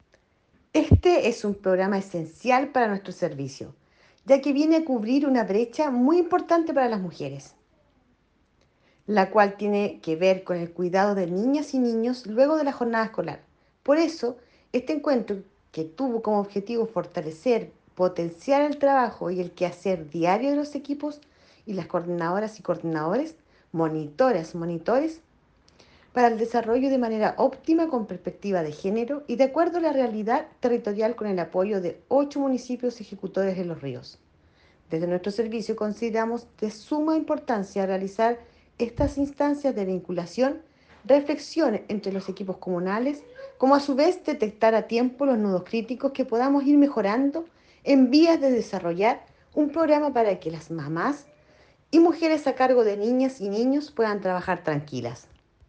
cuña-DIRECTORA-REGIONAL-DE-SERNAMEG-WALESKA-FEHRMANN-ATERO-ENCUENTRO-4-A-7.mp3